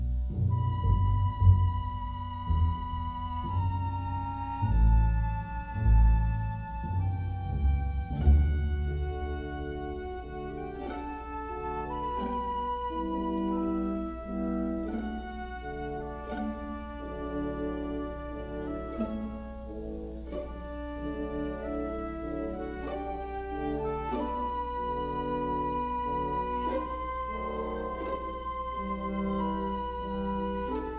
"enPreferredTerm" => "Musique orchestrale"